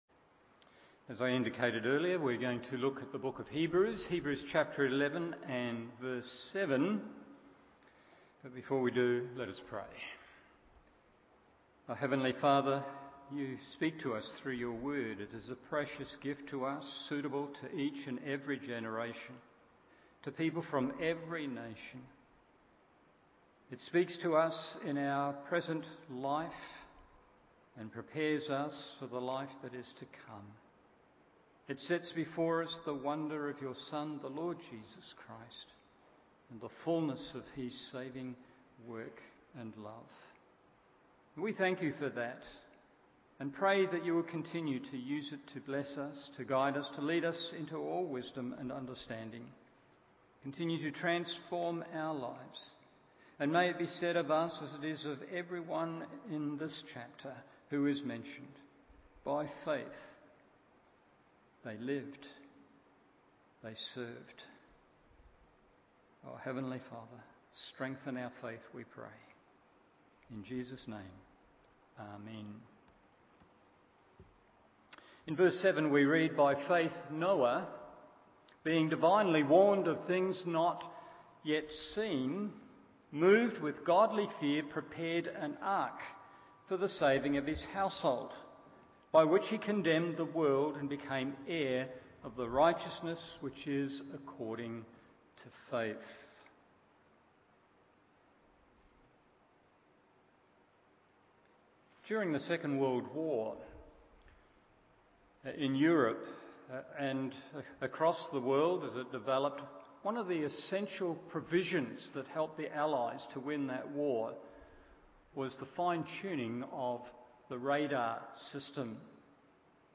Evening Service Hebrews 11:7 1. A Coming Judgment 2. A Contrasting Exception 3.